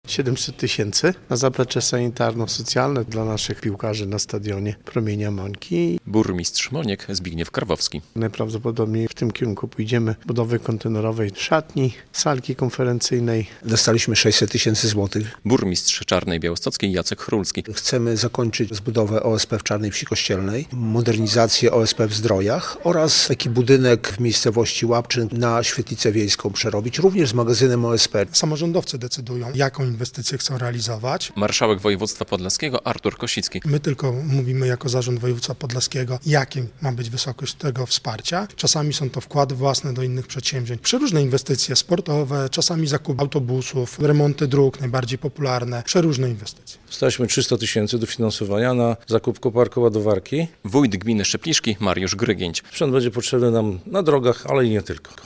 119 podlaskich gmin i powiatów otrzyma 61 mln zł z budżetu województwa podlaskiego na wsparcie różnych inwestycji. Symboliczne czeki samorządy dostały we wtorek w Białymstoku; wcześniej dotacje przyznali formalnie radni sejmiku województwa.
Rekordowe 61 mln zł trafi do podlaskich samorządów z Funduszu Wsparcia Gmin i Powiatów - relacja